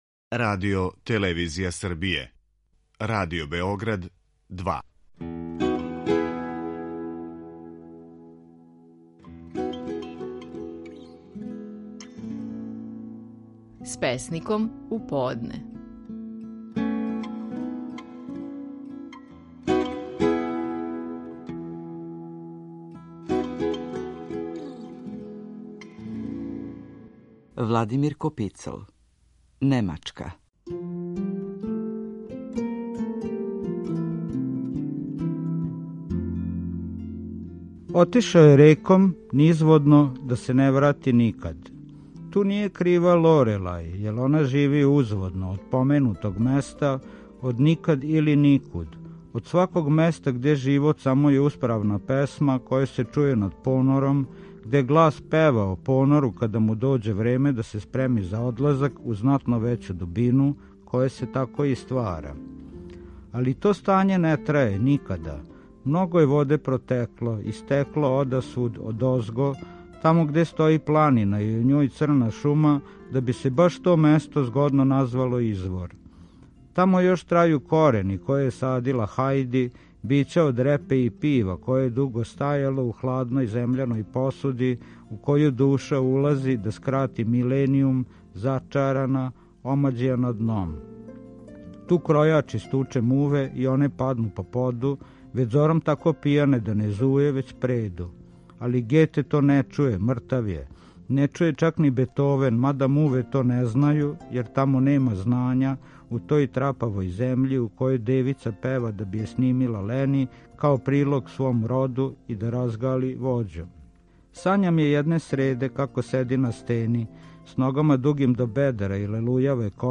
Стихови наших најпознатијих песника, у интерпретацији аутора.
Владимир Копицл говори стихове песме „Немачка".